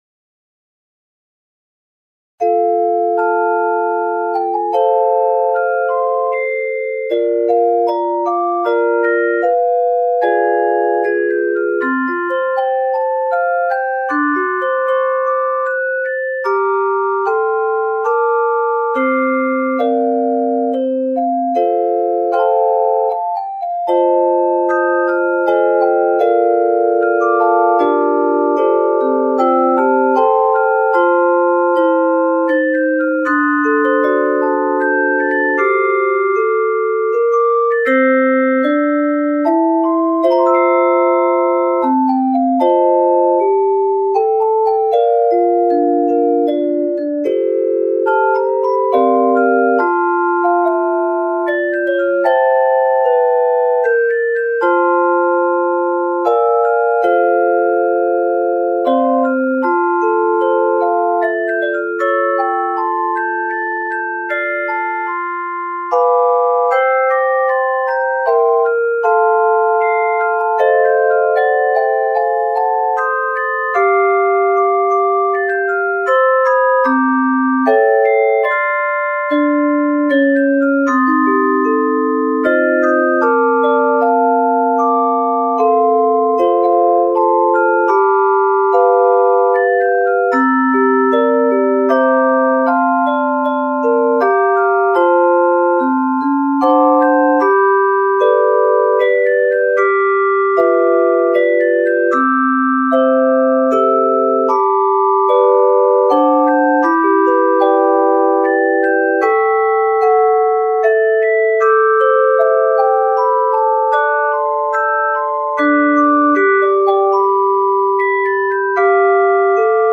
This version is arranged with a gentle jazz lilt.
Keys of F and G Major.